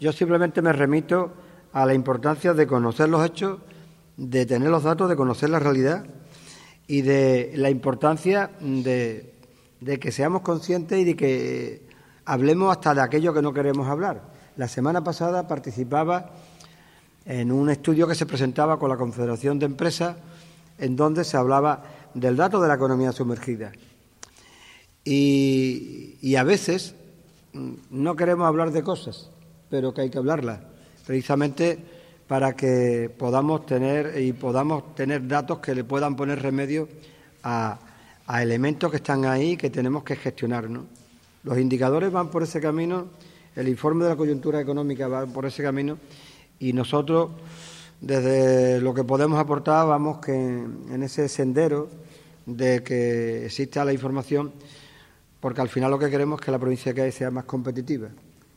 Corte de José María Román